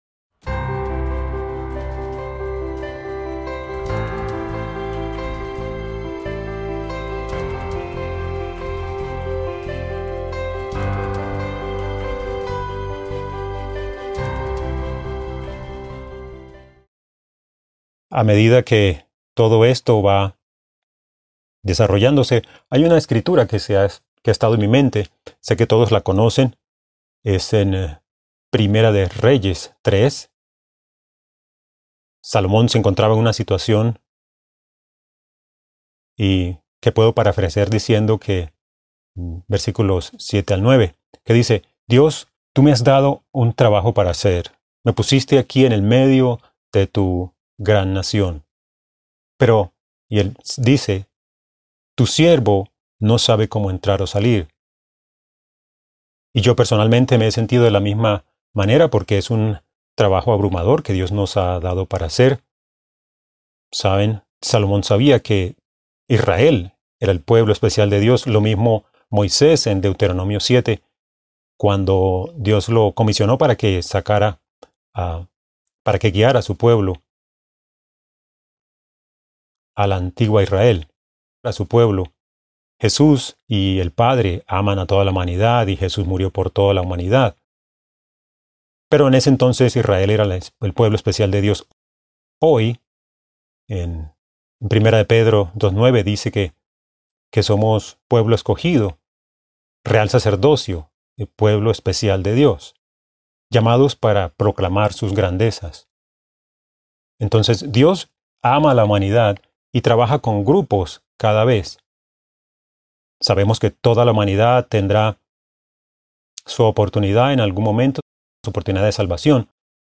Este sermón se pronunció en el lugar de Fiesta de Cincinnati, Ohio 2018.